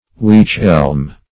weech-elm - definition of weech-elm - synonyms, pronunciation, spelling from Free Dictionary Search Result for " weech-elm" : The Collaborative International Dictionary of English v.0.48: Weech-elm \Weech"-elm`\, n. (Bot.)